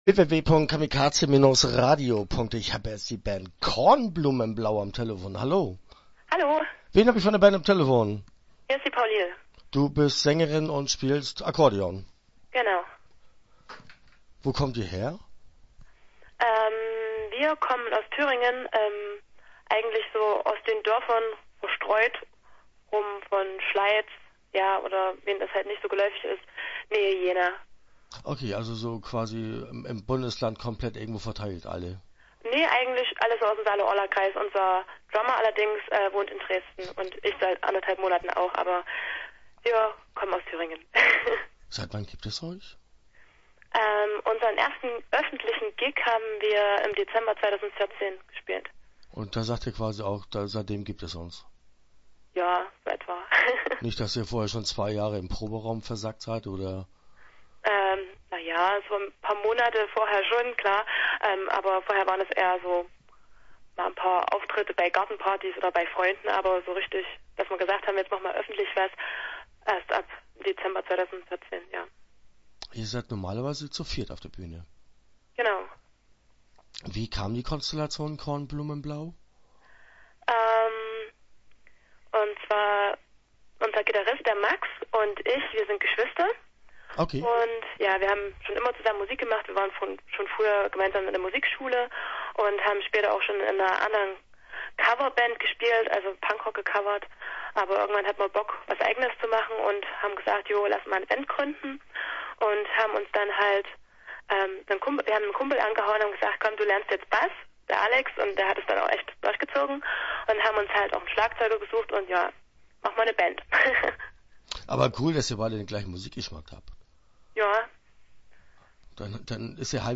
Start » Interviews » Kornblumenblau